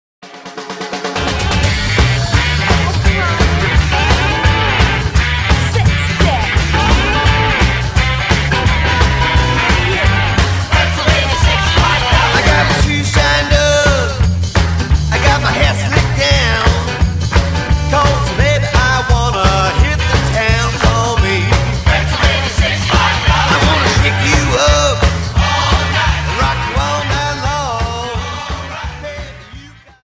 Dance: Jive 43 Song